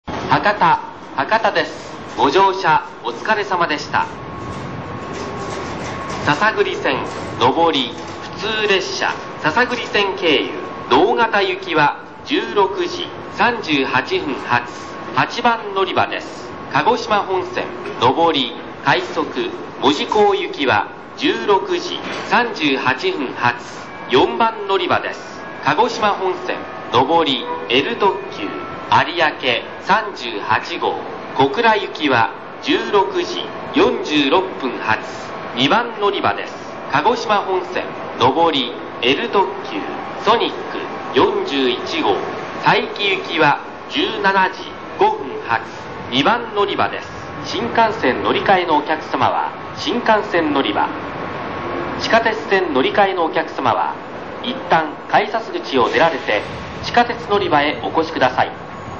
旧 １番のりば 到着放送（男性） （２代目α放送-2001年10月5日まで　289KB/59秒）
出入りする列車が非常に多く、ホ−ムではたいていどこかで放送が流れています。そのため音を録っていると必ず他のホ−ムの音が入ってしまいます。